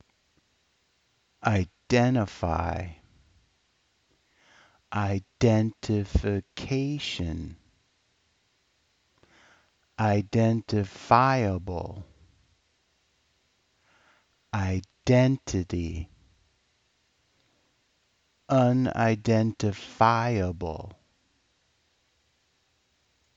• iDENtify
• identifiCAtion
• identiFIable
• iDENtity
• unidentiFIable